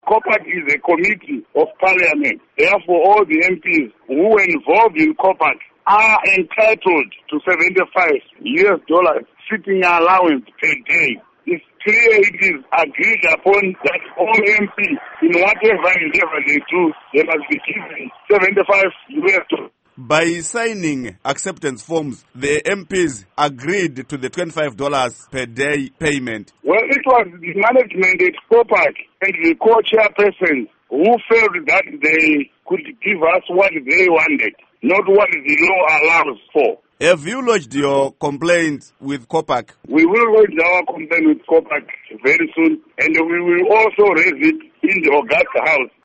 Interview With Simbaneuta Mudarikwa